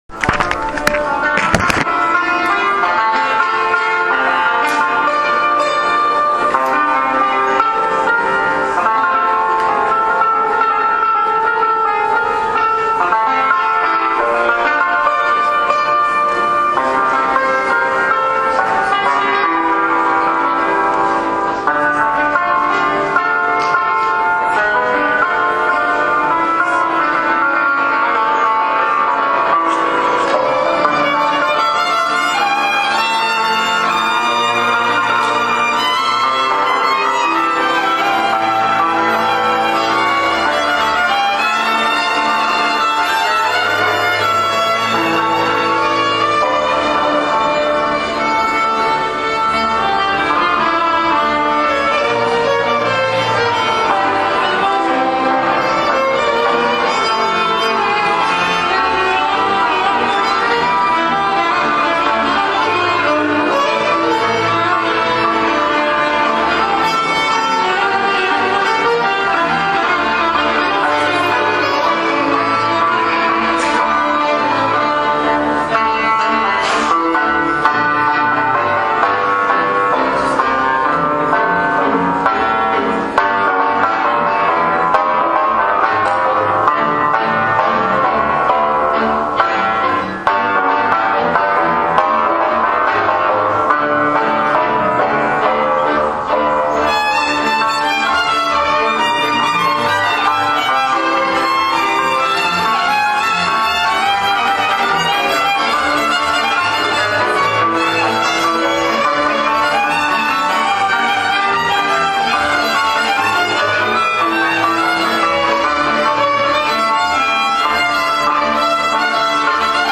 Kulturák byl přeplněný, někteří lidé museli i stát.
mladá cimbálová muzika
slušnou úroveň měli i lidoví vypravěči, kteří vystupovali.